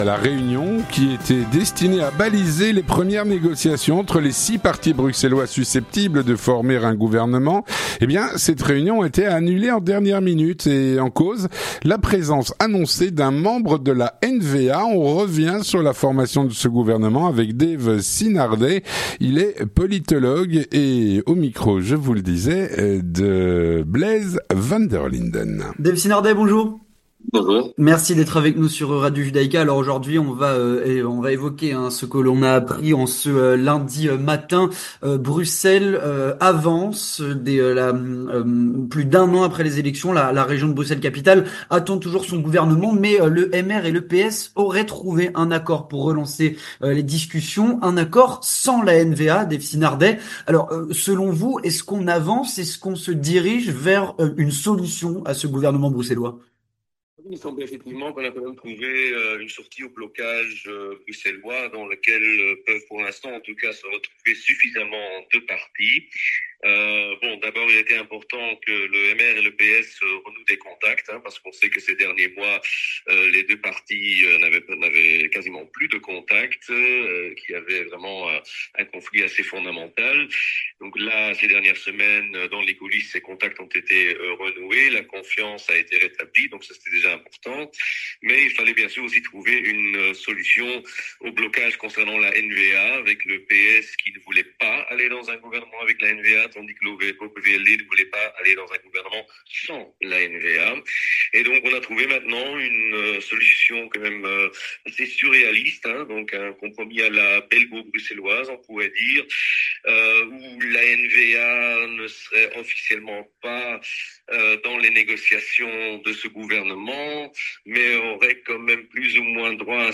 politologue.